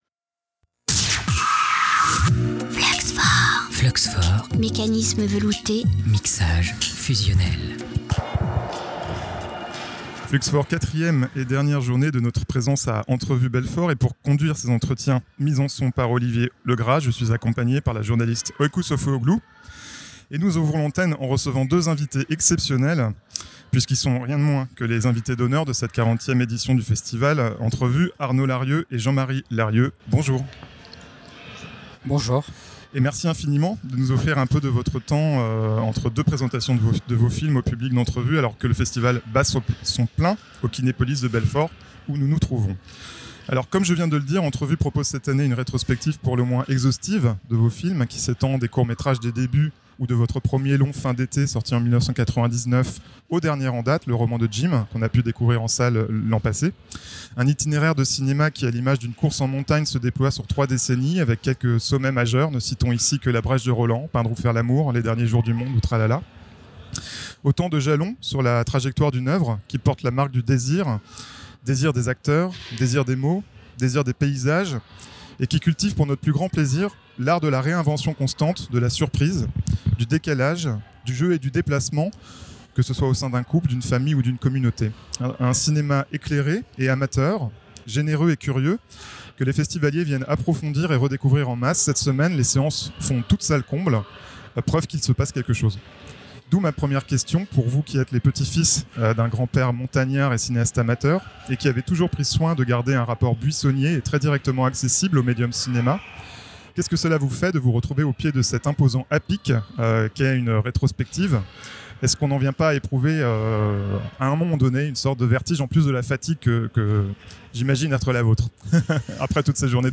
Rencontre avec les frères Arnaud et Jean-Marie Larrieu, Entrevues 2025
Chaque film a été une aventure en soi, comme des sommets isolés dans différents massifs, entre les Pyrénées, les Alpes, et désormais le Jura." audio/mpeg Les frères Arnaud et Jean-Marie Larrieu, invités d'honneur de la 40ᵉ édition du festival Entrevues de Belfort, évoquent à notre micro leur parcours de trois décennies de création cinématographique. Ils comparent leur trajectoire à celle de montagnards, où chaque film représente une ascension différente.